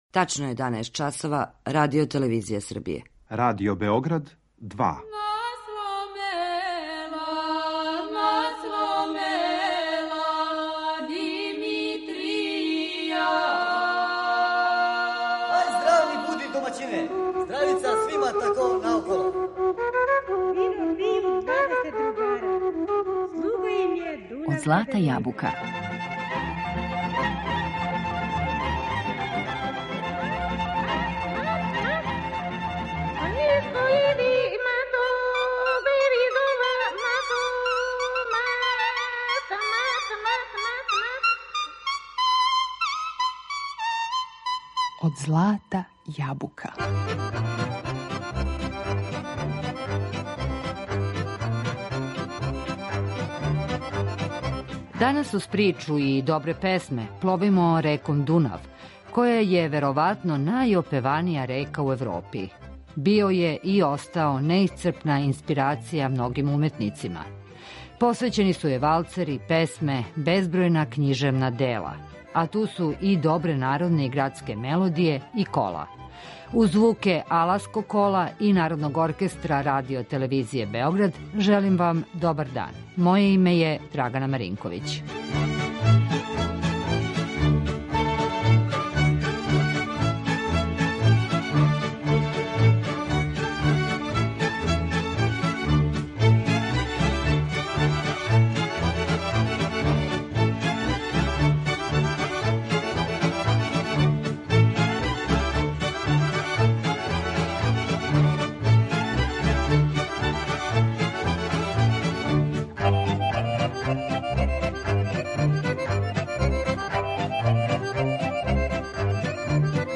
Данас кроз причу и добре песме пловимо реком Дунав.
Неке од најлепших одабрали смо да чујемо у данашњем издању емисије Од злата јабука .